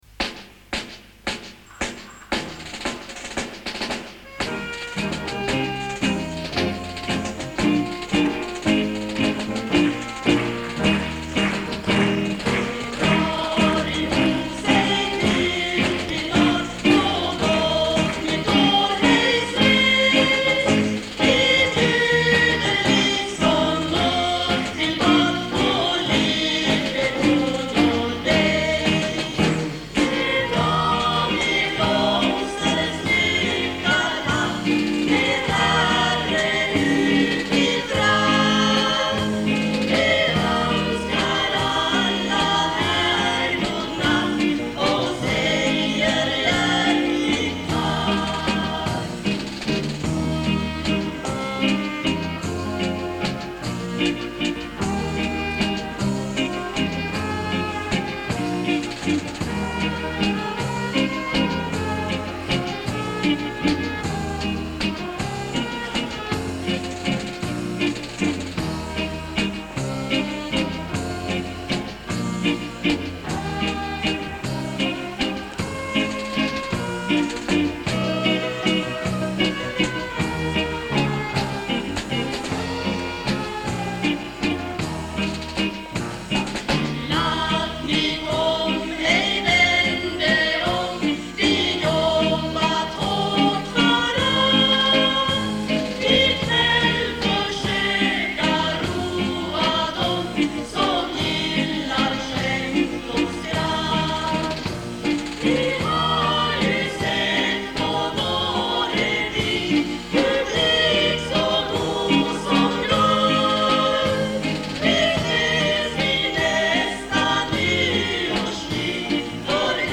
Ljusne revyn 1984, bandat p� plats!